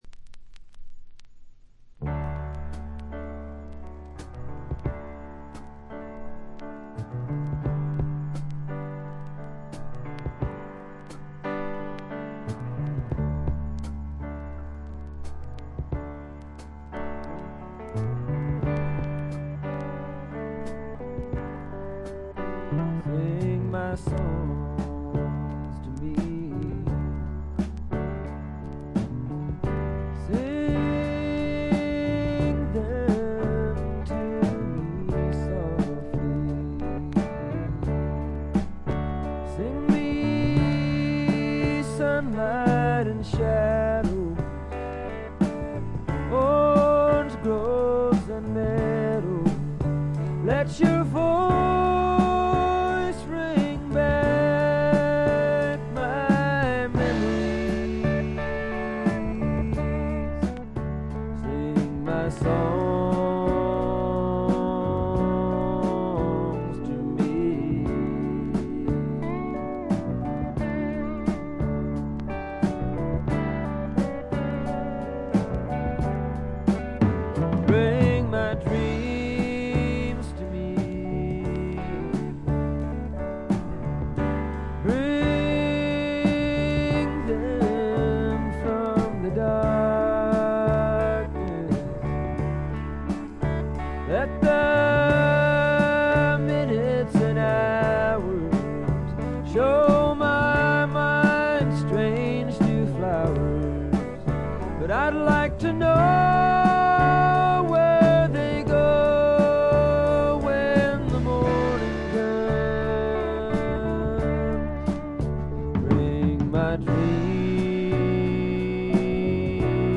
ところどころでチリプチ。
試聴曲は現品からの取り込み音源です。